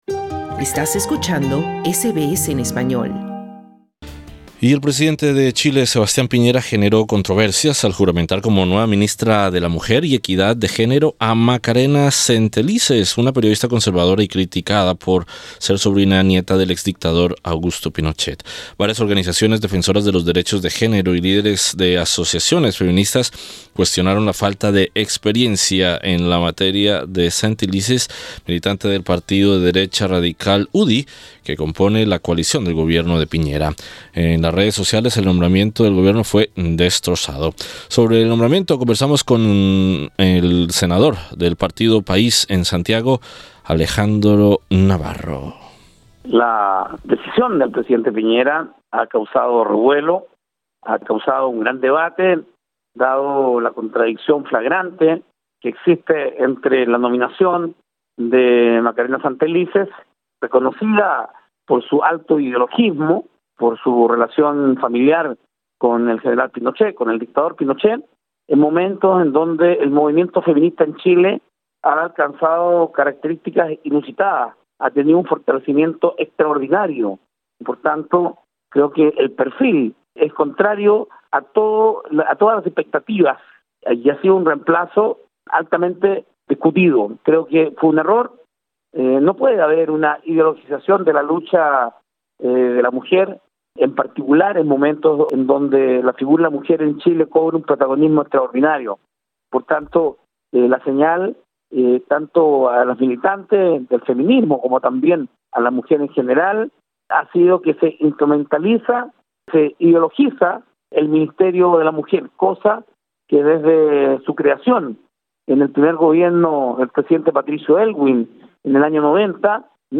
Sobre el nombramiento, conversamos en Santiago con el senador del Partido País, Alejandro Navarro.